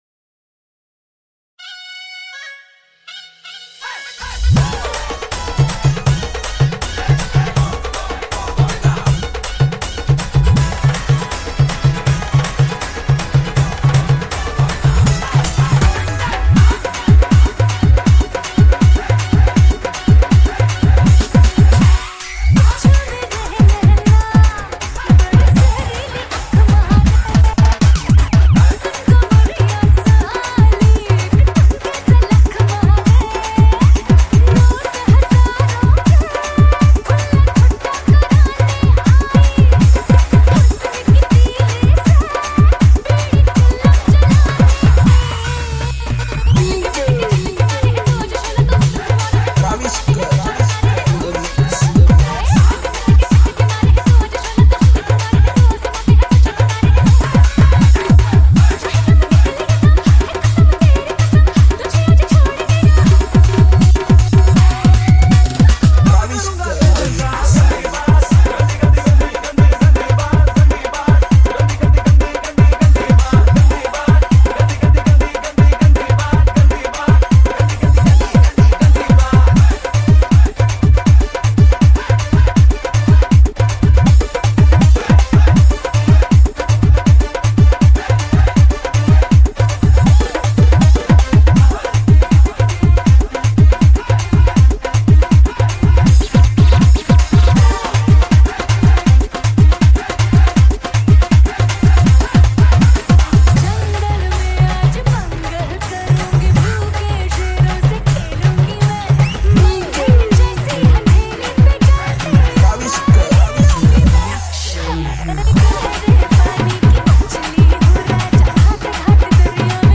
High quality Sri Lankan remix MP3 (8).
Remix